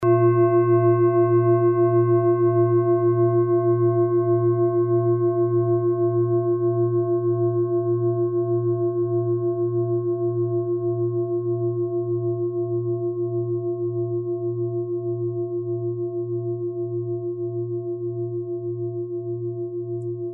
Klangschale Bengalen Nr.21
Klangschale-Durchmesser: 26,3cm
Sie ist neu und wurde gezielt nach altem 7-Metalle-Rezept in Handarbeit gezogen und gehämmert.
(Ermittelt mit dem Filzklöppel)